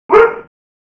woof.wav